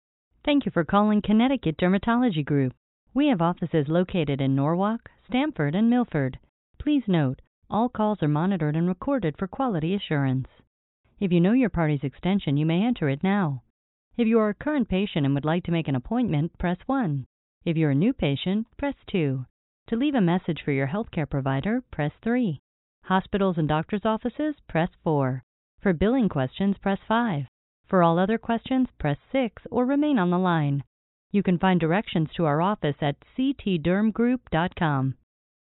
Nobody likes recording their own business telephone greetings, so why not let the professionals do it for you?